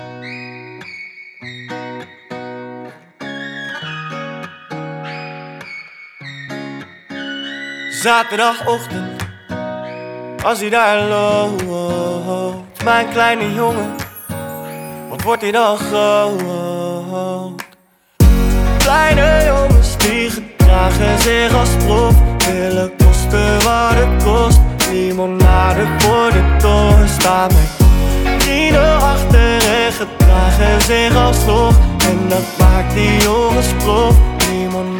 Скачать припев
2021-03-12 Жанр: Поп музыка Длительность